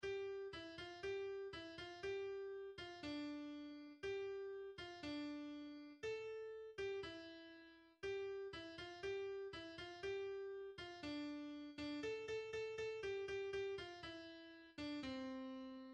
Natuurlijk met heel veel liedjes (
muziekfragment) en bewegings-spelletjes!